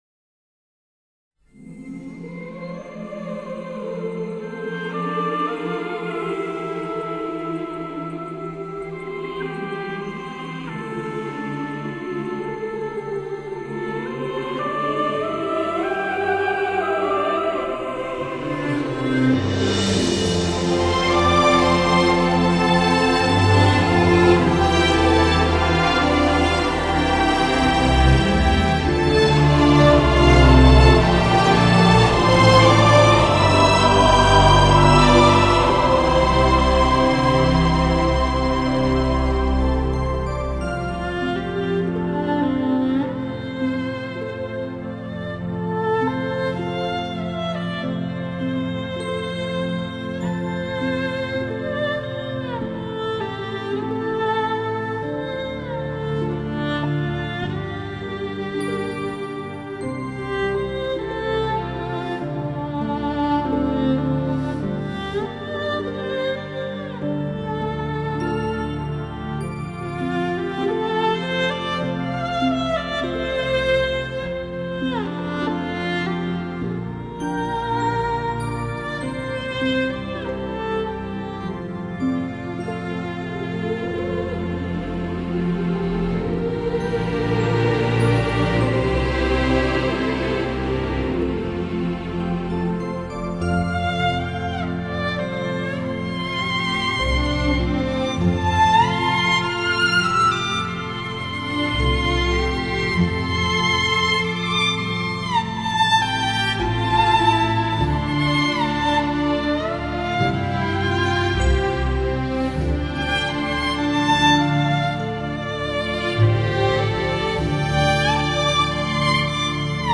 她的演绎讲究音色、乐感和内涵，演奏风格细腻、柔美而不失大家风范。